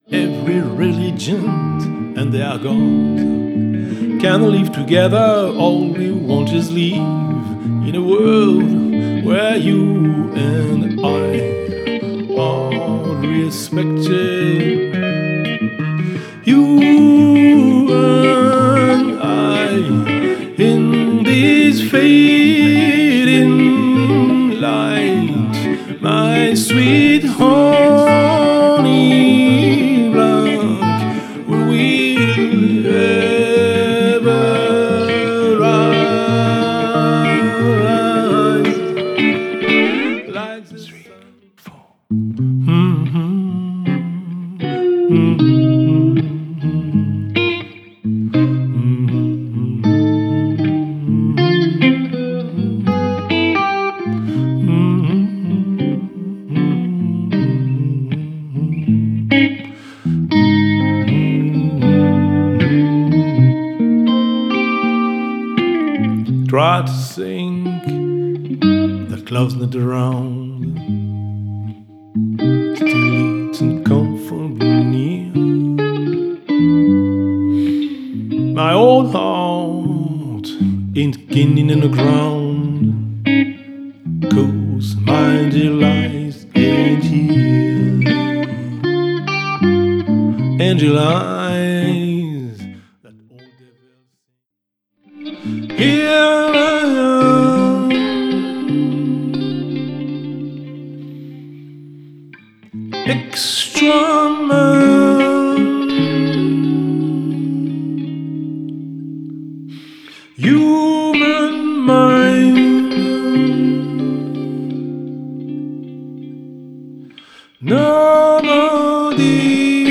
c’est un concert de chansons en anglais
à la guitare électrique et au chant
En duo :